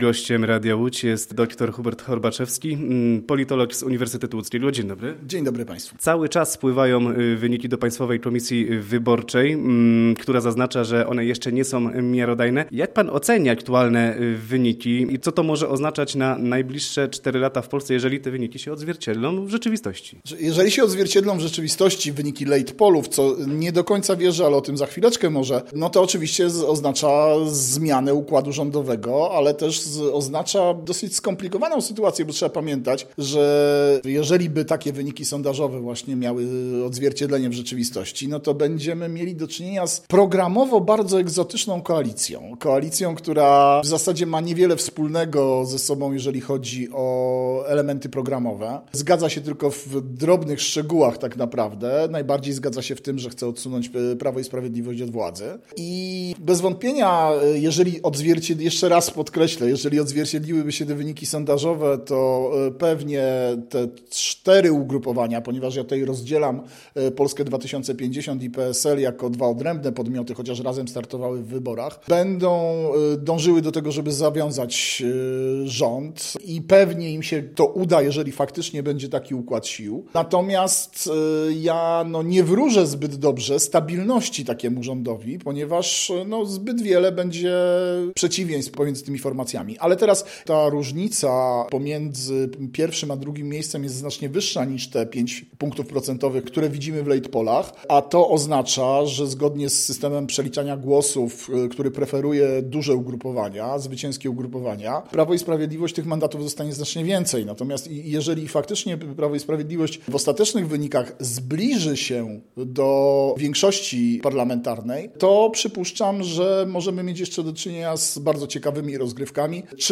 Politolog o rozstrzygnięciach wyborczych Sondażowe wyniki wyborów, które z dużą dozą prawdopodobieństwa będą mocno zbieżne z faktycznymi rozstrzygnięciami, były nieco zaskakujące.